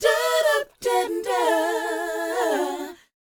DOWOP B DU.wav